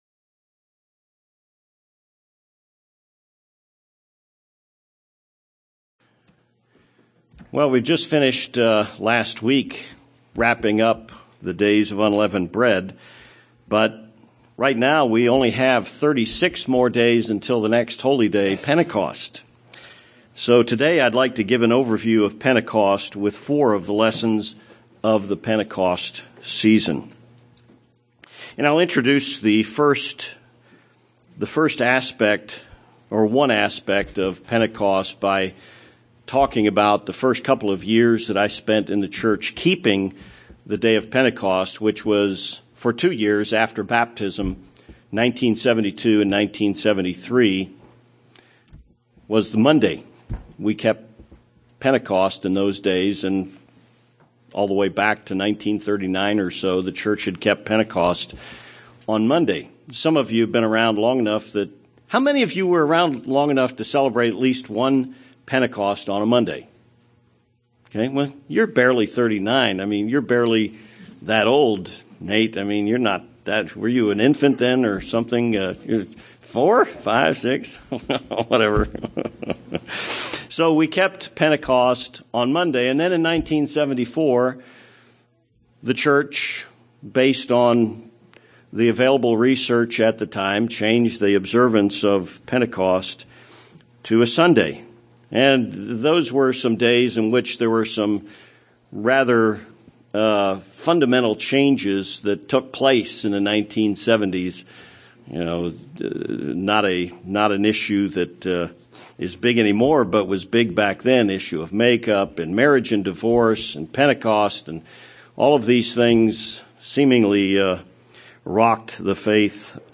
This sermon examines several keys of the last Holy Day season of the Spring, with lessons from the Old and New Testaments.